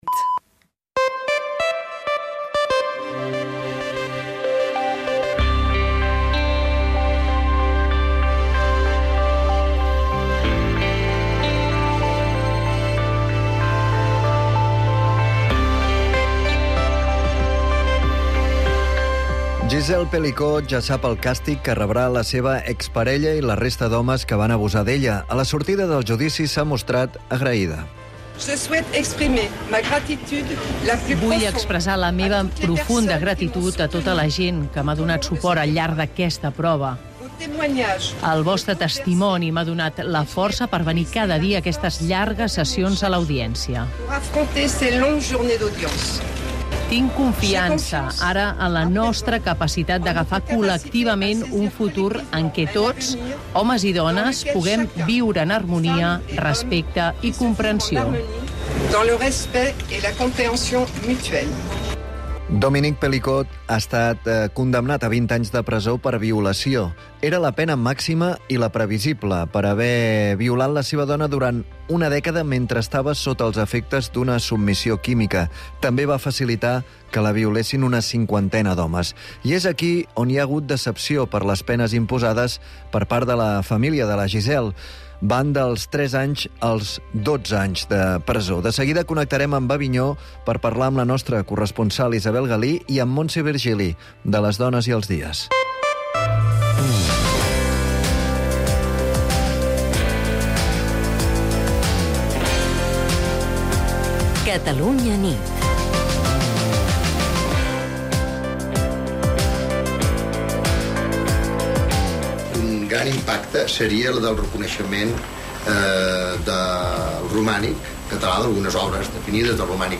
Reproduir episodi Veure més episodis del programa Catalunya vespre RSS feed Veure més episodis del programa Catalunya vespre Veure altres programes de la categoria informatius